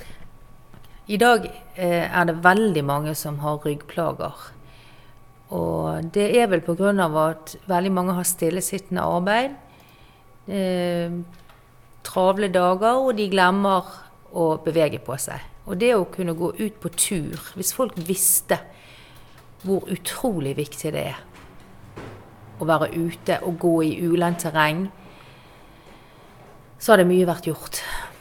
Dialect from Bergen in Norway